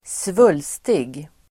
Ladda ner uttalet
Uttal: [²sv'ul:stig]